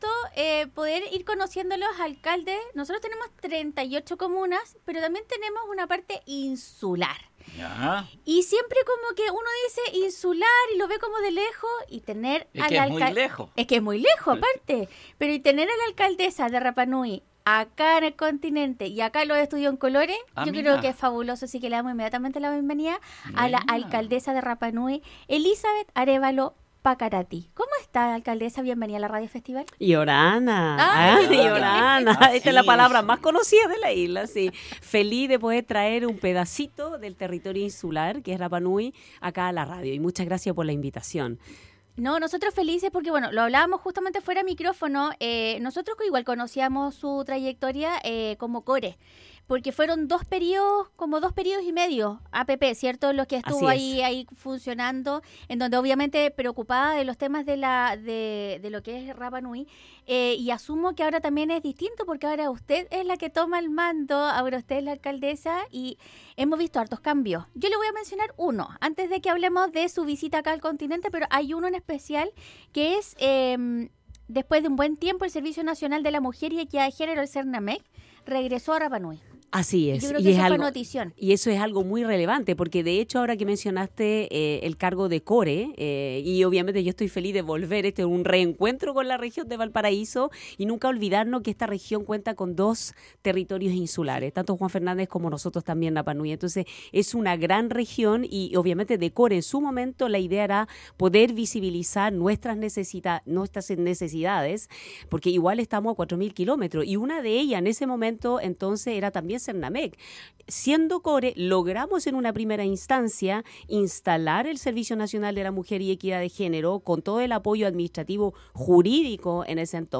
Alcaldesa de Rapa Nui Visita los estudios de Radio Festival